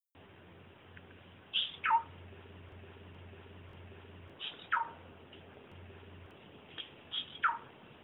Pheugopedius fasciatoventris Black-bellied Wren Soterrey Vientrinegro
wrenblackbellied.wav